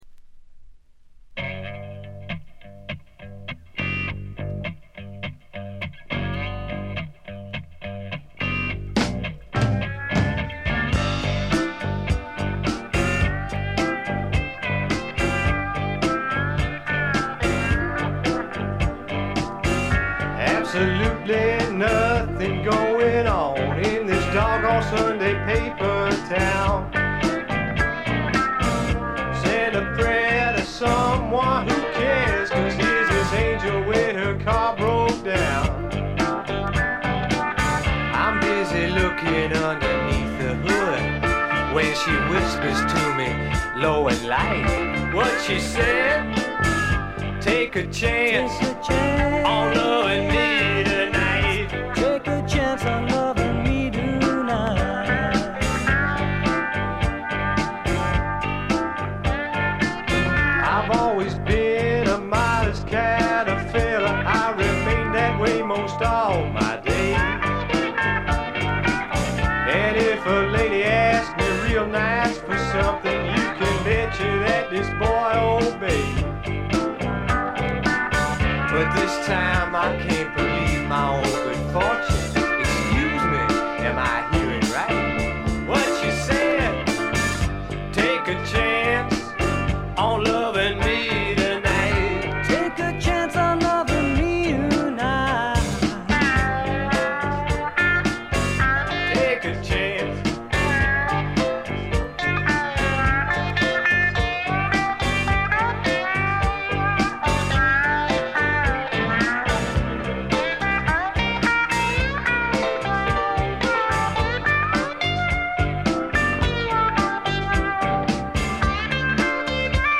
ジャケットはまるで激渋の英国フォークみたいですが、中身はパブロック／英国スワンプの裏名盤であります。
カントリー風味、オールド・ロックンロールを元にスワンプというには軽い、まさに小粋なパブロックを展開しています。
試聴曲は現品からの取り込み音源です。